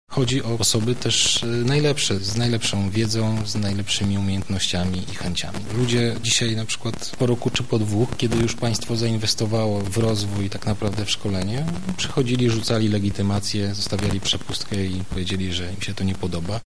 -mówi Maciej Materka, szef Służby Kontrwywiadu Wojskowego.